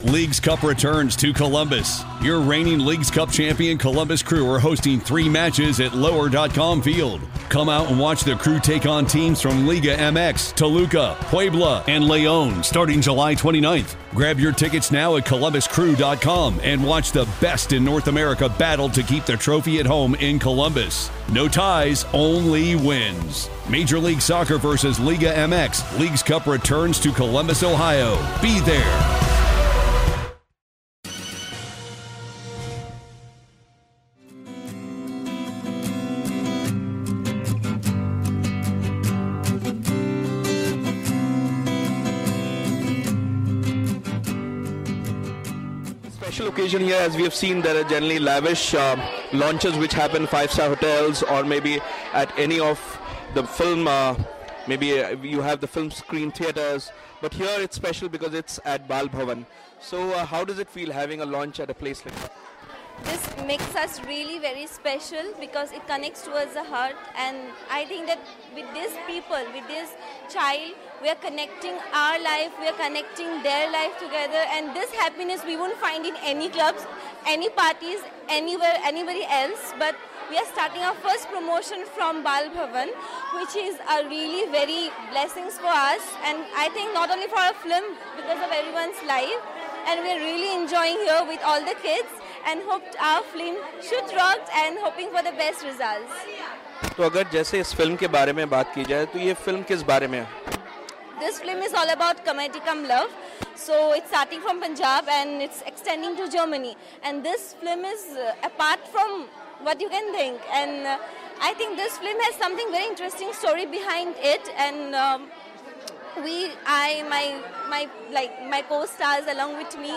Exclusive Interview
It was a memorable event for various reasons at the Mahurat of Hindi film Raja Abroadiya with NGO kids of Bal Bhawan,Andheri West.